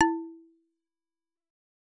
content/hifi-public/sounds/Xylophone/E2.L.wav at e79c92efd75c32eaa3205bdce35426b74af6a207